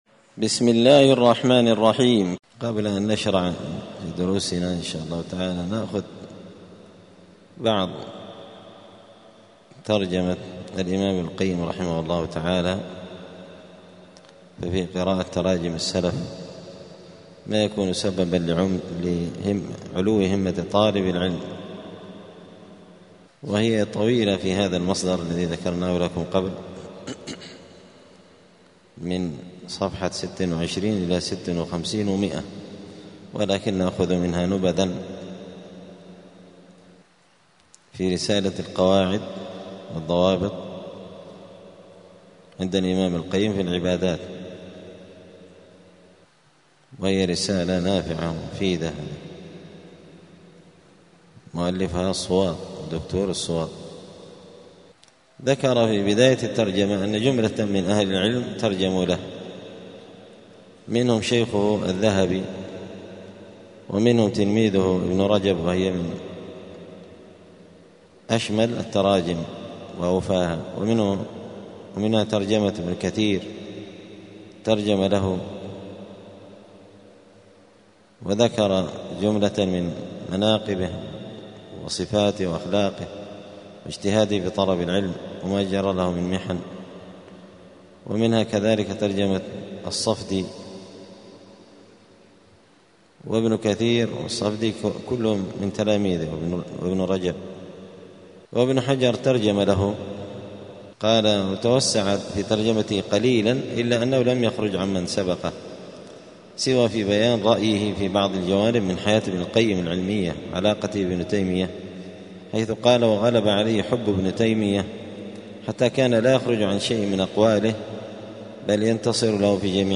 *الدرس الأول (1) {اسمه ونسبه وبلده ونشأته}*